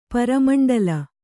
♪ para maṇḍala